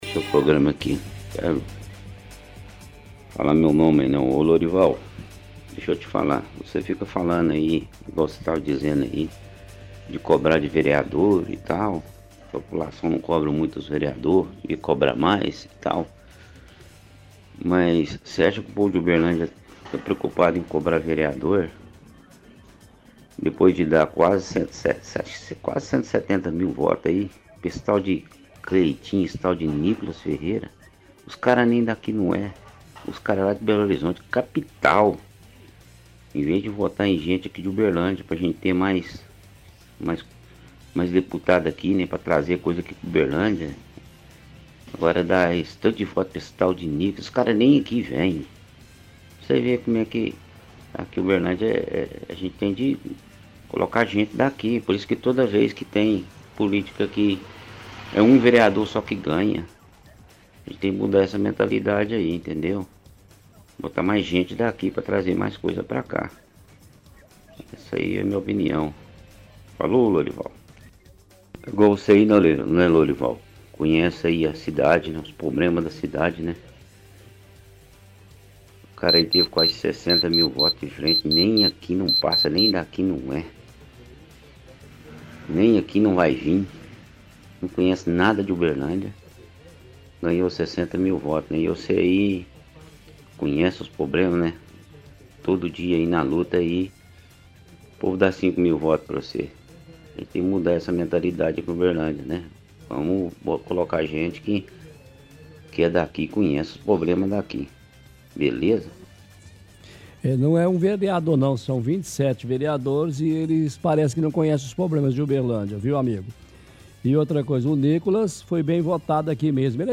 – Ouvinte fala que o povo de Uberlândia não está preocupado em cobrar vereadores, reclama dos nossos deputados representantes que não são nem de Uberlândia, como o deputado federal Nikolas Ferreira que não faz nada pela cidade.